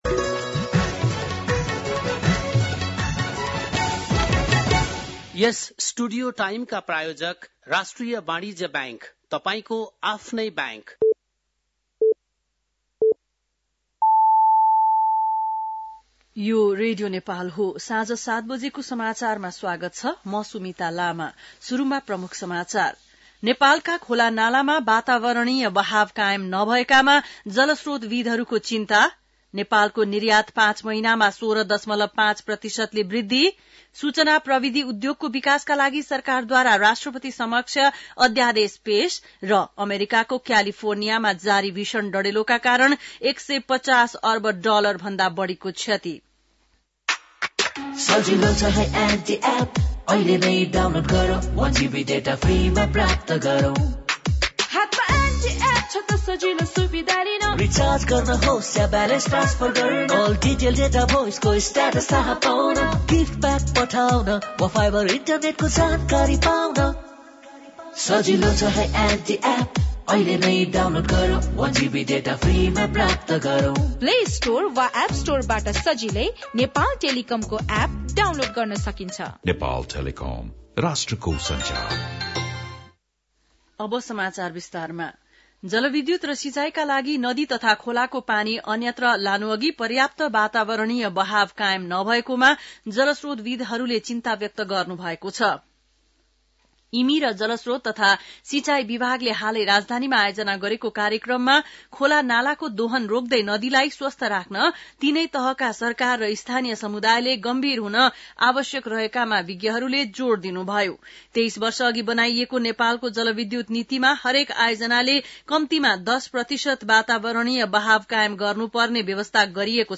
बेलुकी ७ बजेको नेपाली समाचार : २८ पुष , २०८१
7-pm-news.mp3